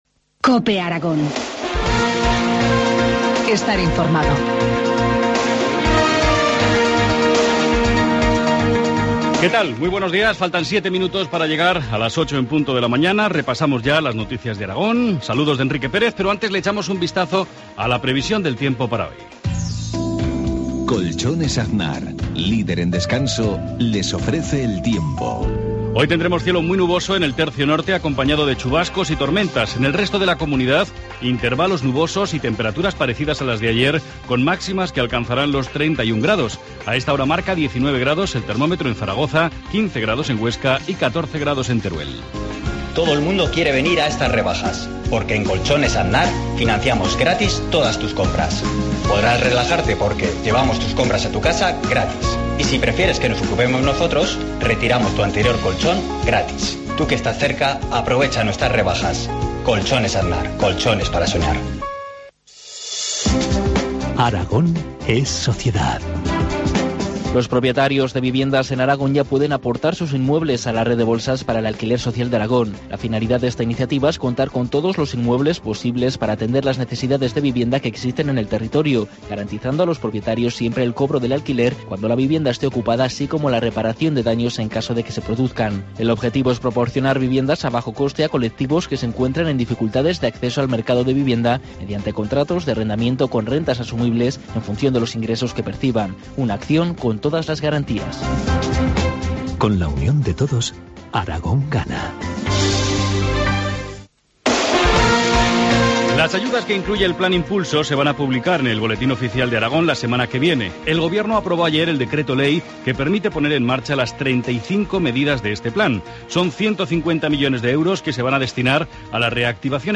Informativo matinal, viernes 6 de septiembre, 7.53 horas